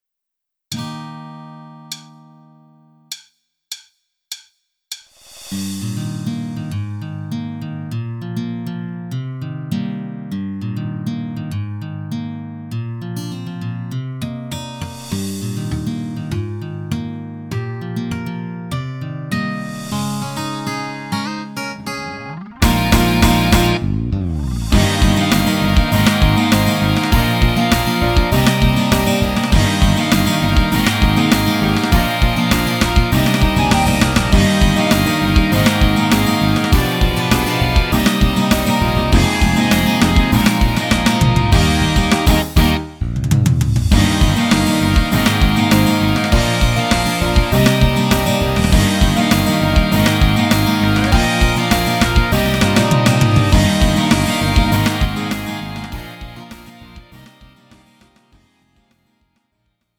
음정 원키 3:31
장르 가요 구분 Lite MR